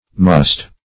Moste \Mos"te\, obs.